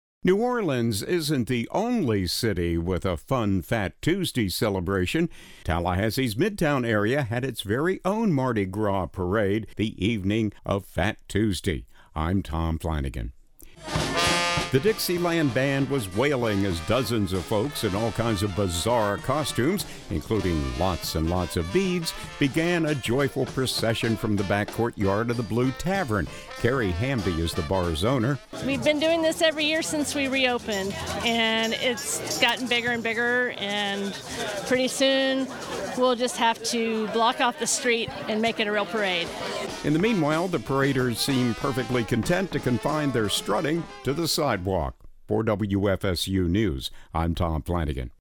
Tallahassee’s Midtown area had its very own Mardi Gras parade on last night before Ash Wednesday.
The Dixieland band was wailing as dozens of folks in all kinds of bizarre costumes – including lots and lots of beads – began a joyful procession from the back courtyard of the Blue Tavern.
mardisgrasparade0218full.mp3